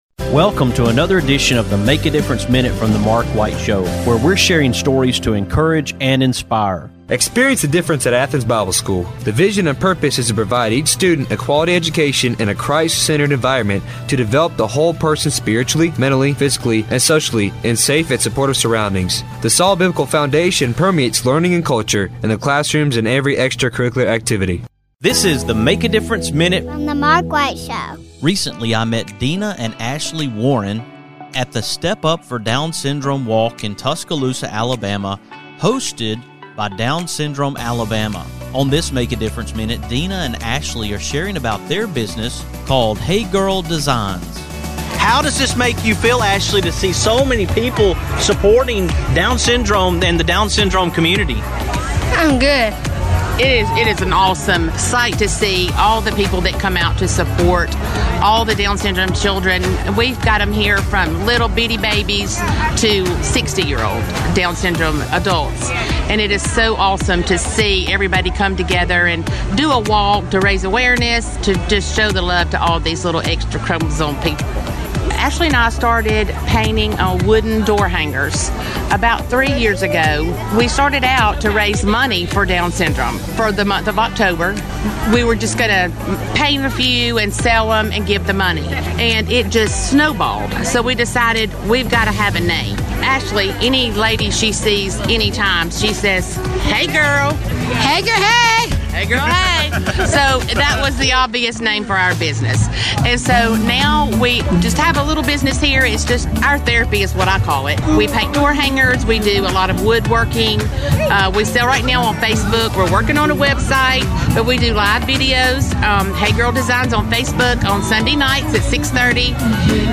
I was able to talk with both of them at the Down Syndrome Alabama Step Up for Down Syndrome Walk in Tuscaloosa, Alabama. I hope you will listen and share as we bring awareness to Down syndrome.